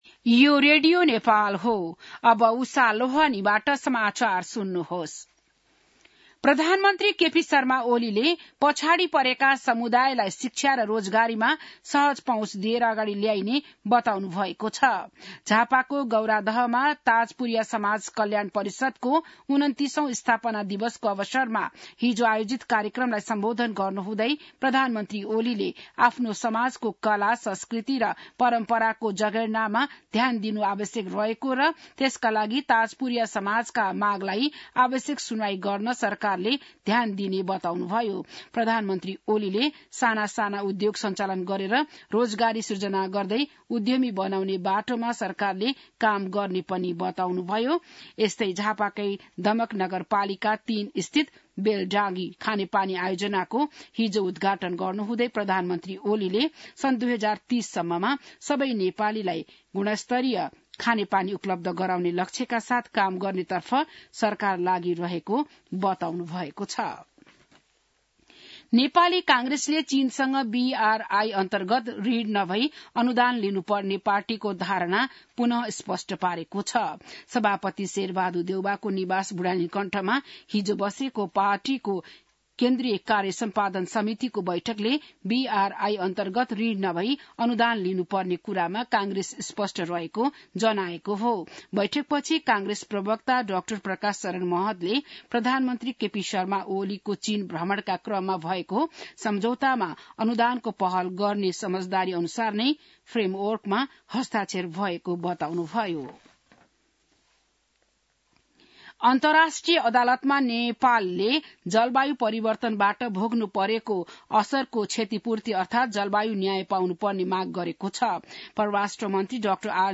बिहान १० बजेको नेपाली समाचार : २६ मंसिर , २०८१